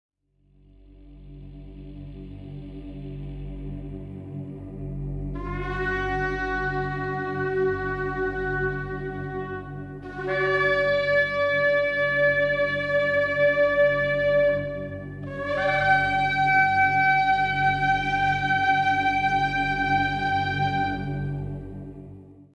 shofar.mp3